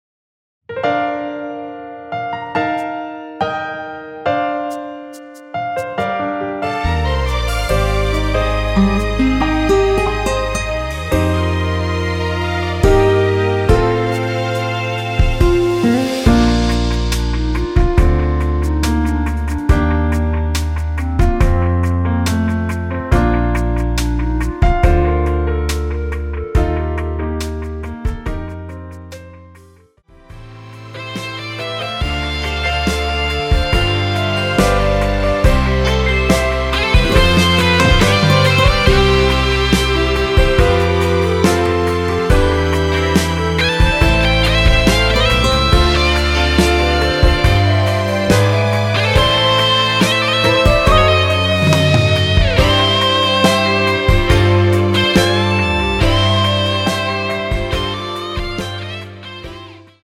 원키에서(-10)내린 멜로디 포함된 MR입니다.
Bb
노래방에서 노래를 부르실때 노래 부분에 가이드 멜로디가 따라 나와서
앞부분30초, 뒷부분30초씩 편집해서 올려 드리고 있습니다.
중간에 음이 끈어지고 다시 나오는 이유는